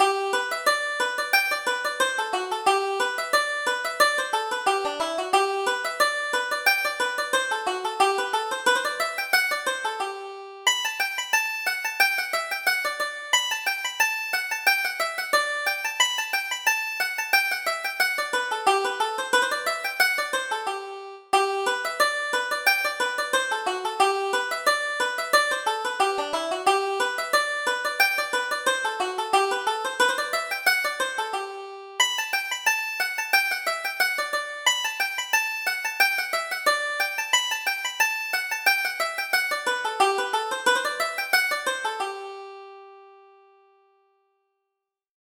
Reel: All Hands Around